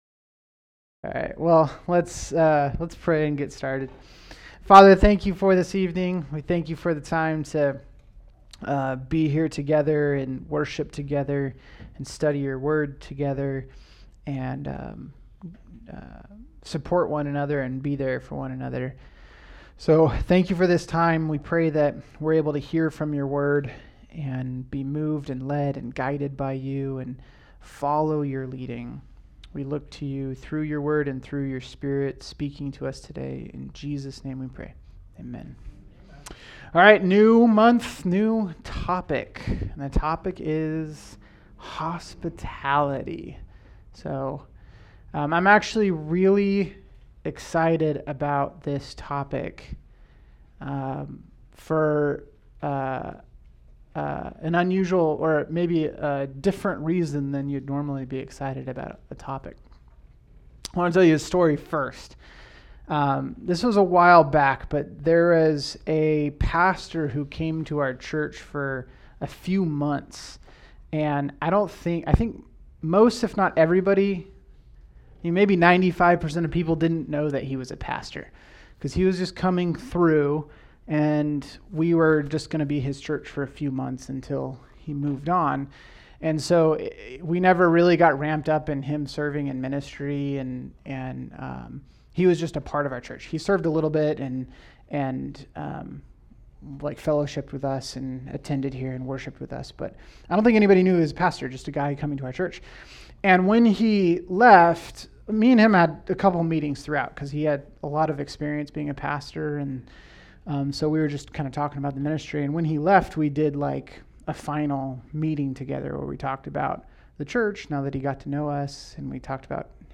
All Sermons What is hospitality?